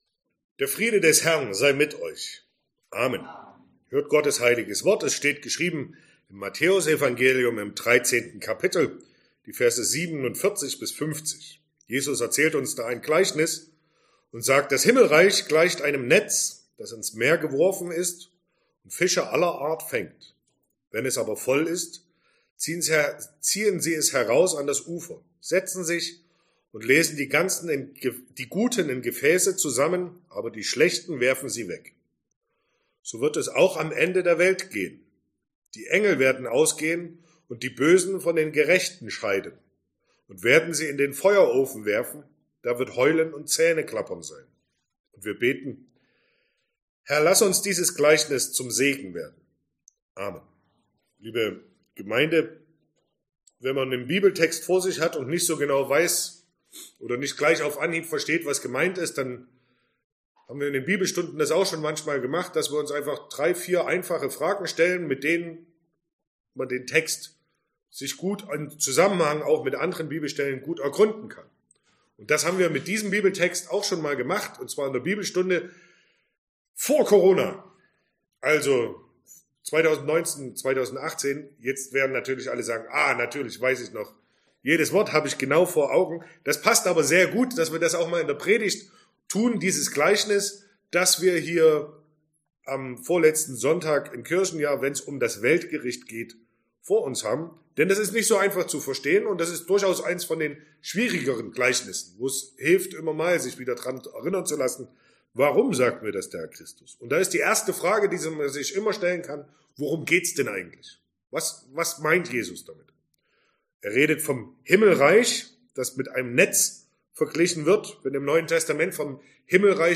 Vorletzter Sonntag im Kirchenjahr Passage: Matthäus 13, 47-50 Verkündigungsart: Predigt « 22.